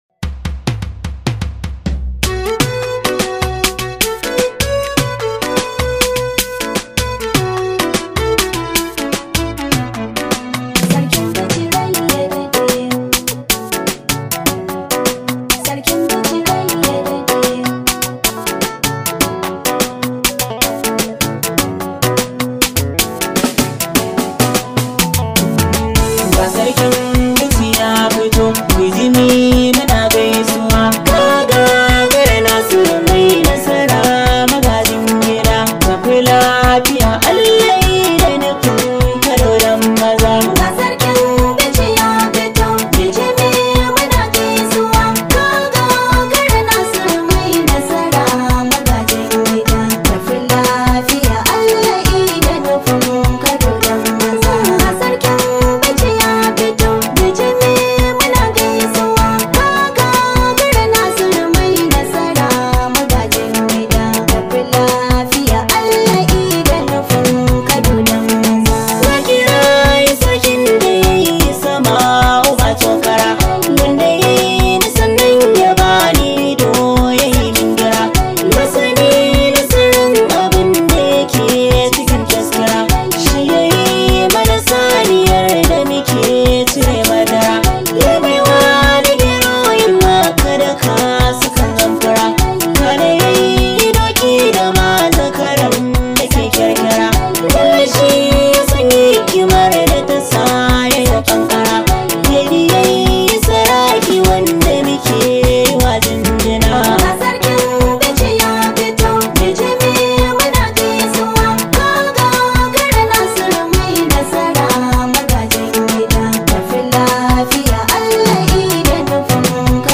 Play back singer